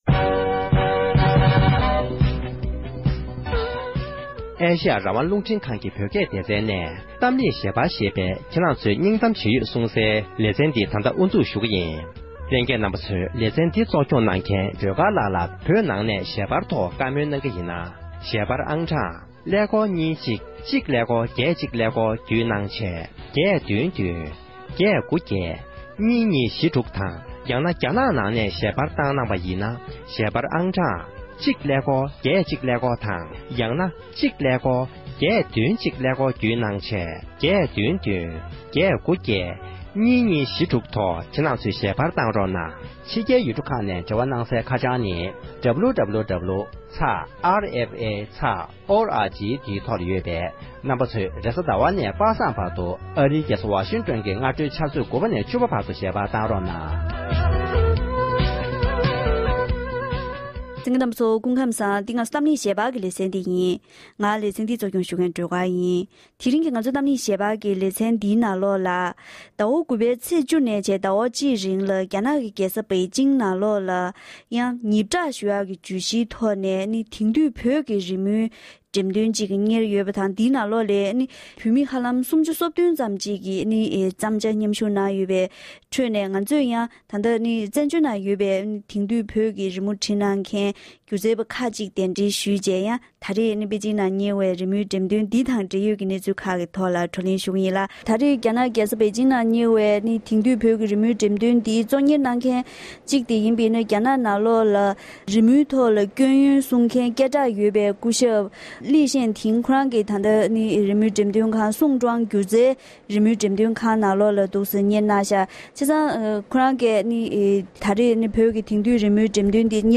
གཏམ་གླེང་ཞལ་པར་གྱི་ལེ་ཚན་ནང་
གླེང་མོལ་